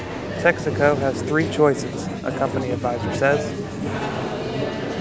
noisy